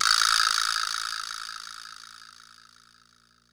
VbraLong.wav